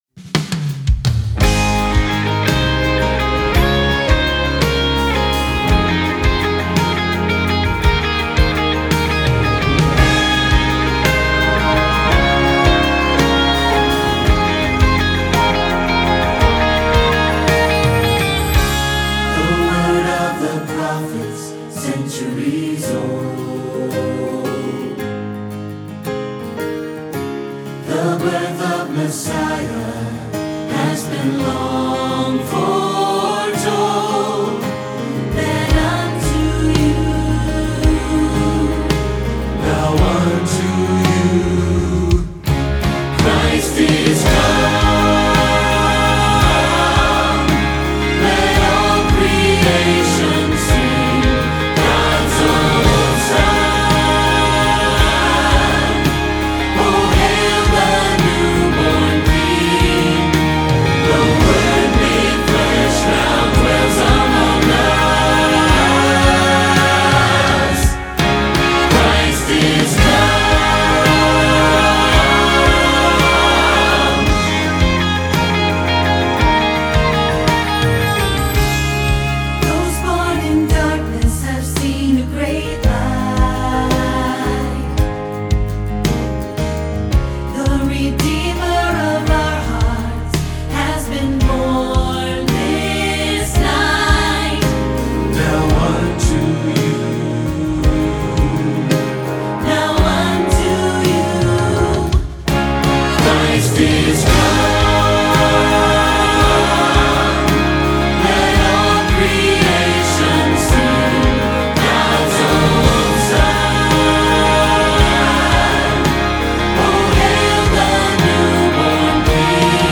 SATB and Piano